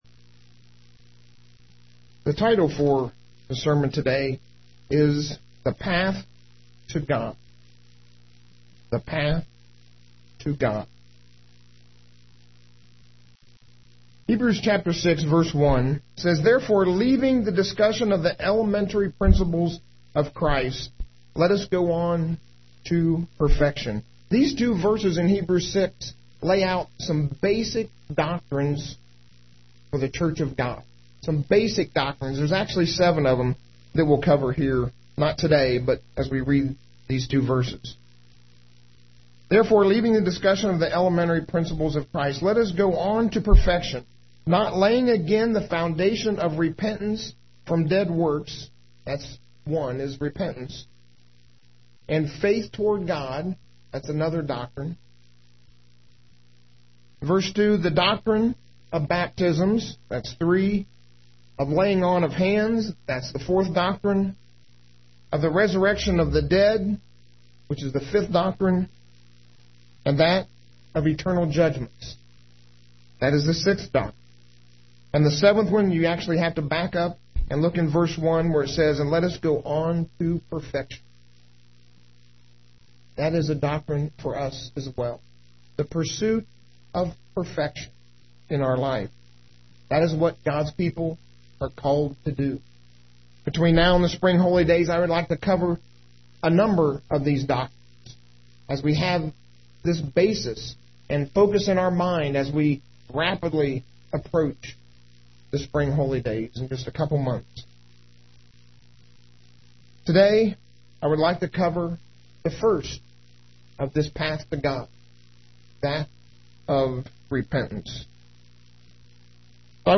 UCG Sermon Notes Notes: Every destination has a path that leads you to that destination.